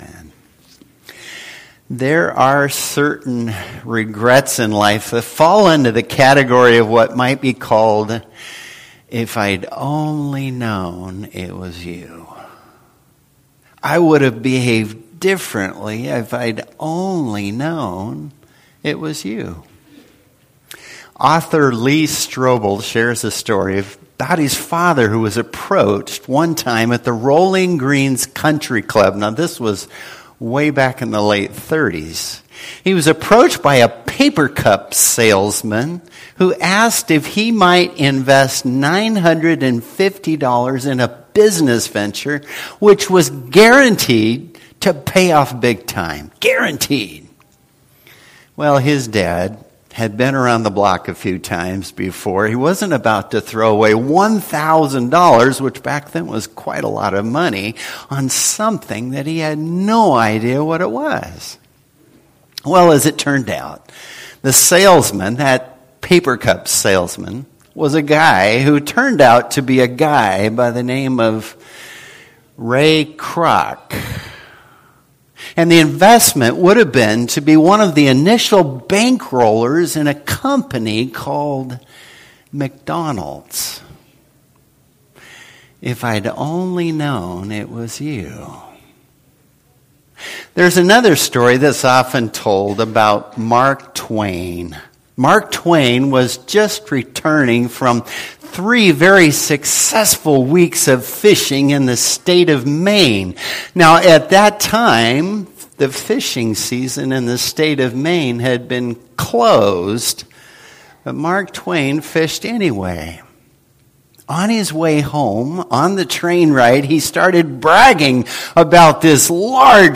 Service Type: Worship Service